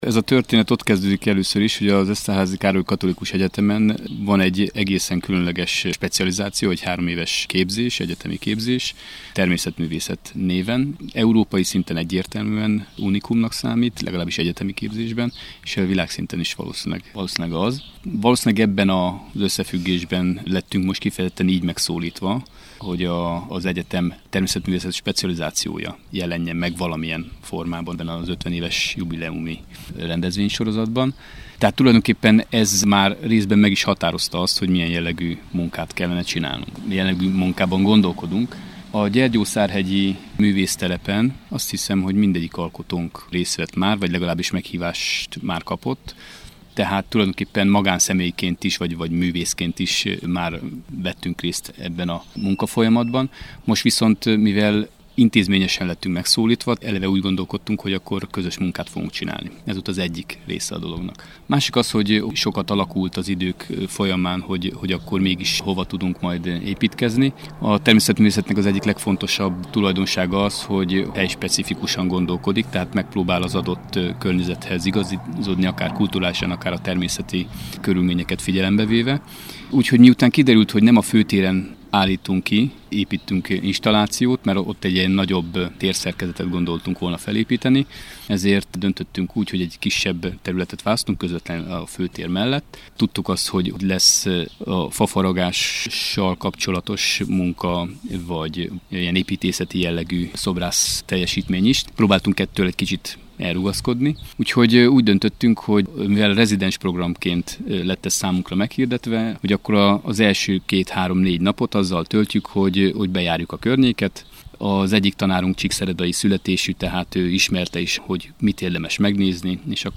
Erről beszélgetünk